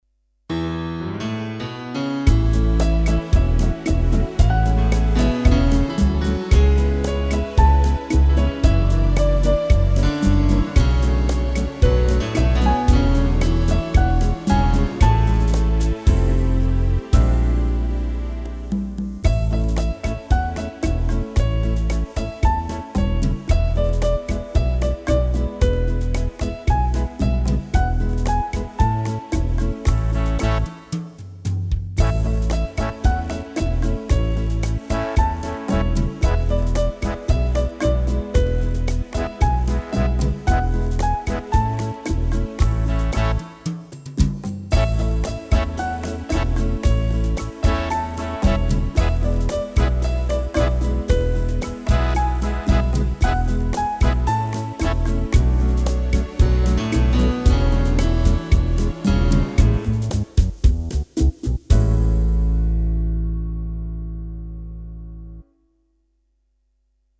Der spilles lang forspil –
Start med at synge efter 19 sekunder